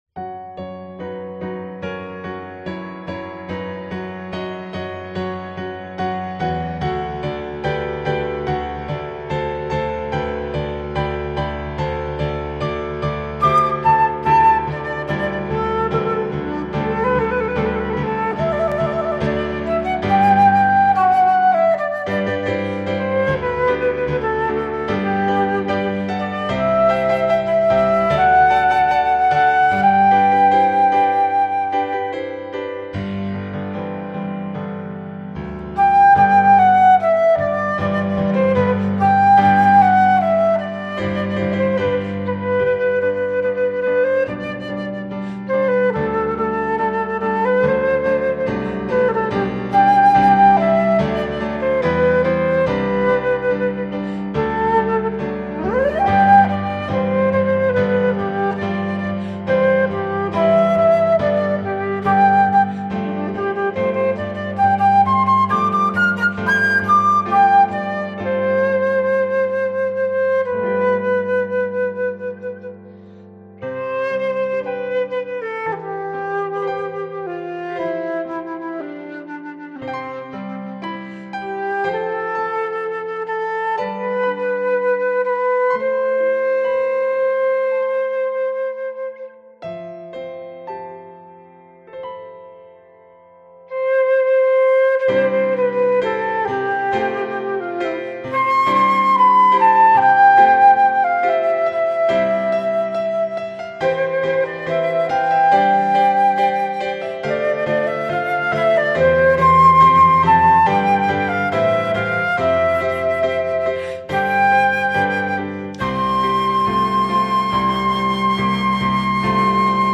spirited and yet reverent arrangement
Flute demo:
Flute & Piano
Christian, Christmas, Sacred